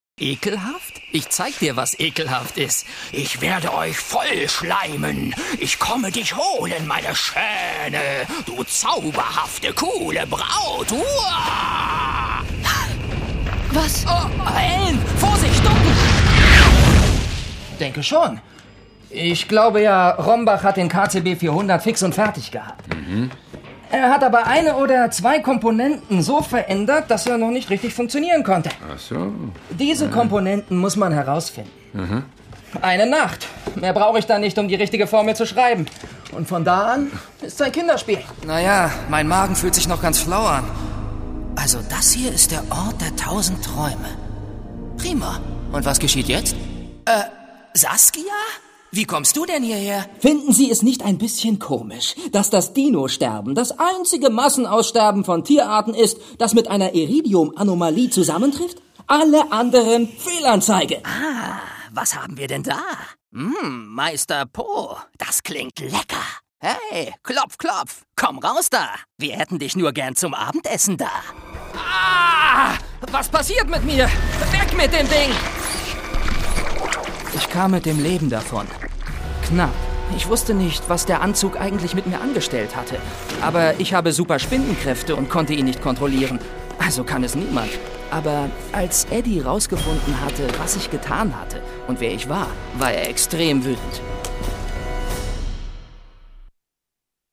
Die junge Stimme mit Erfahrung.
Im Bereich Off / Voice Over biete ich Ihnen eine angenehme Stimmfärbung, mal abseits der sonst typischen tief / markanten Erzählstimme.
Junge Stimme, Sprecher für: Werbung, Audio Ads, Dokumentation, E-Learning, Zeichentrick, Jingles, Lieder, Synchron, etc.
Sprechprobe: eLearning (Muttersprache):